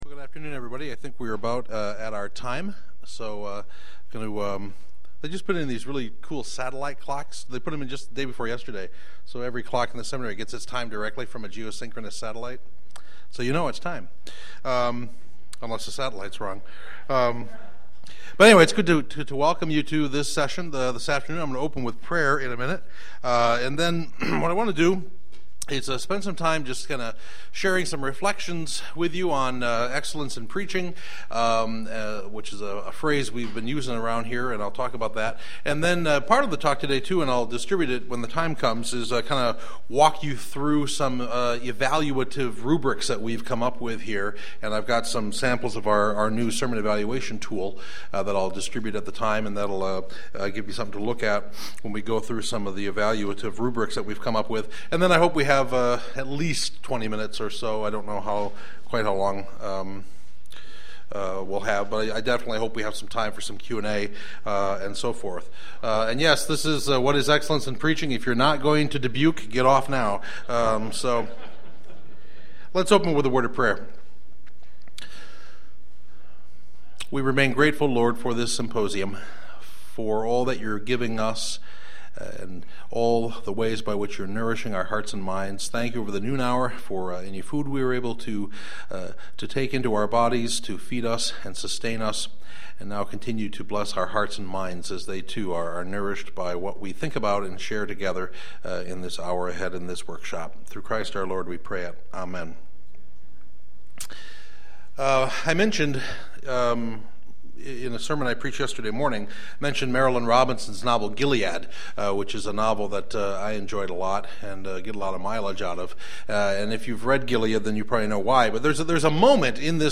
Presented at the 2007 Calvin Symposium on Worship.